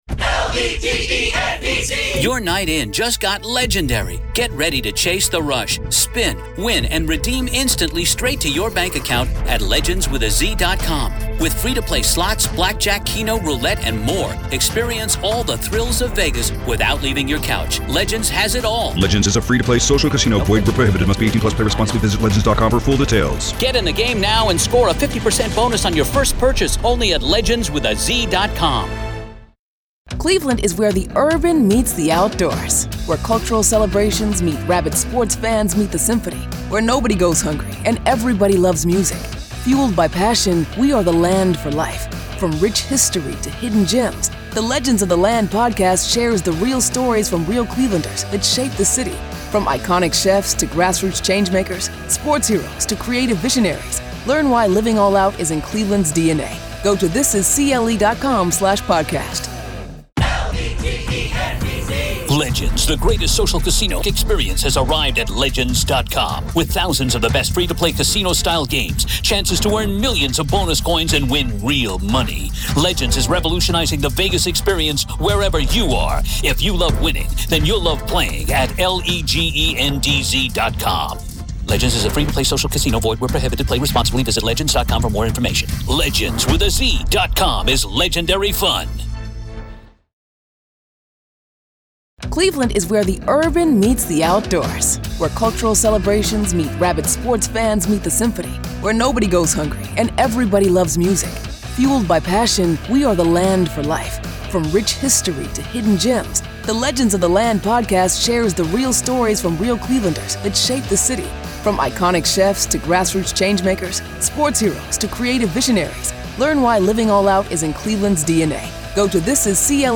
The conversation explores how platforms like Discord and online gaming chats become breeding grounds for extremist thinking, not through shadowy “radicalizers,” but through peer-to-peer validation.